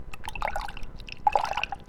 Skippy Fish Water Sound Collection
Here's a collection of all the sound effects used in my game Skippy Fish. I made these with a glass of water, a straw, some popsicle sticks, and my own voice.
swim.ogg